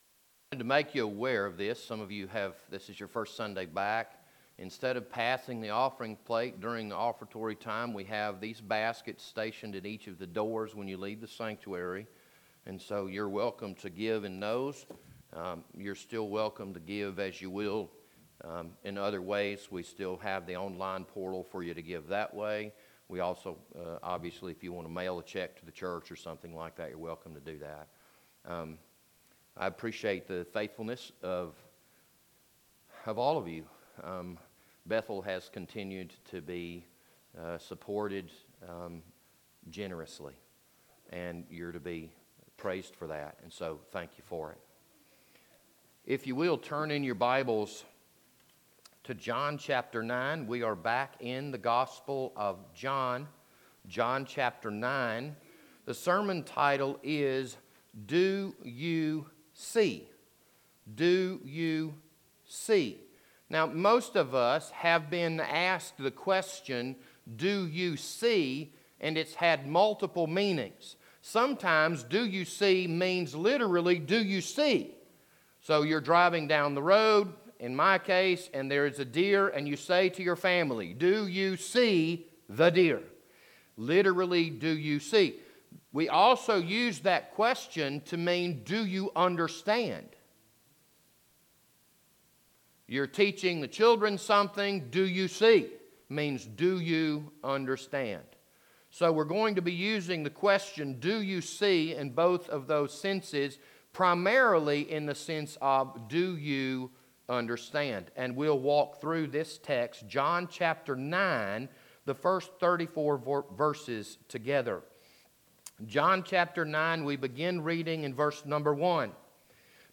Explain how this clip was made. This Sunday morning sermon was recorded for June 7th, 2020.